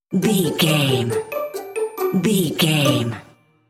Ionian/Major
D
bouncy
cheerful/happy
lively
playful
uplifting
percussion
strings